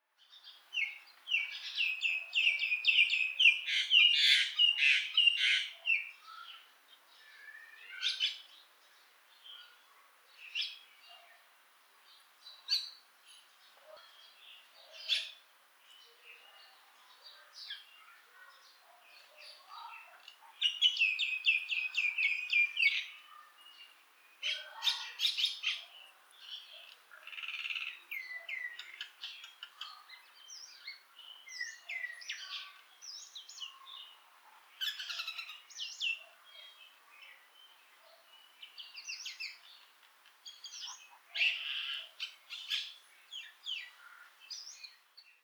Acridotheres tristis tristis
field recording